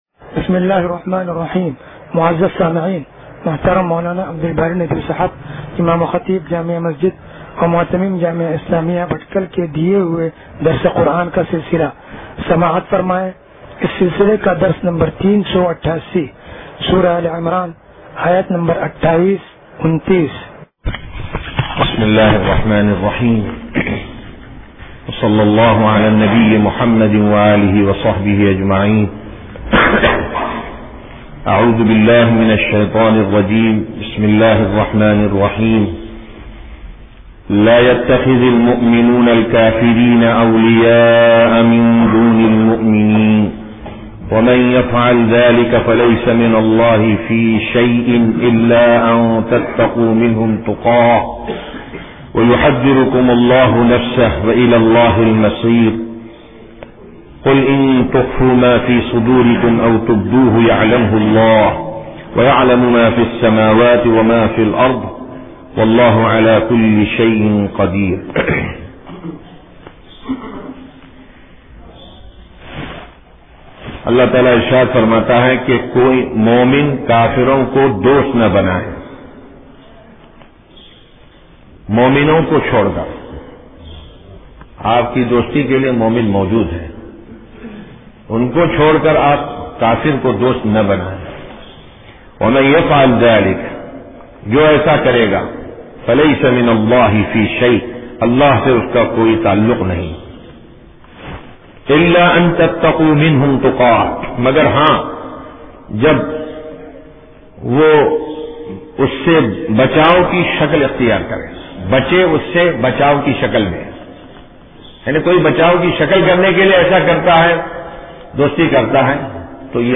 درس قرآن نمبر 0388